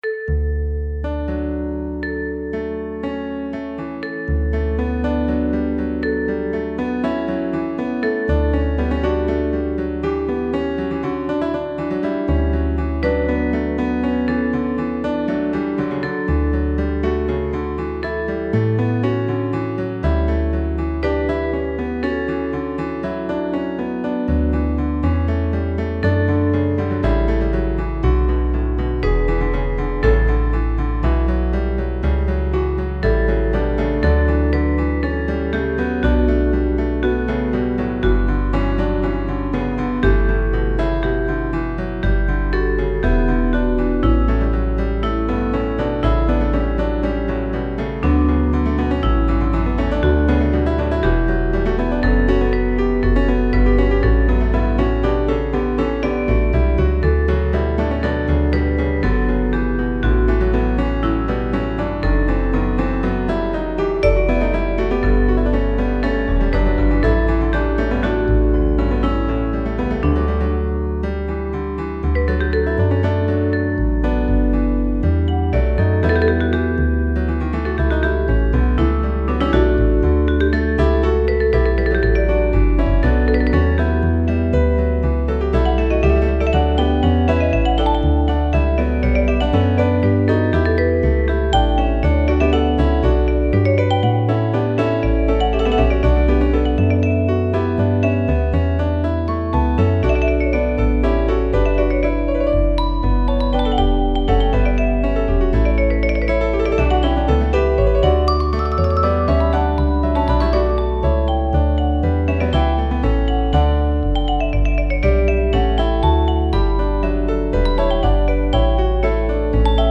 Lasst uns froh und munter sein (Text & Melodie: Josef Annegarn, 1794 − 1843) Aus einem besinnlich präludierenden Anfang entwickelt sich ein munter kontrapunktierendes und vor Allem swingendes Trio.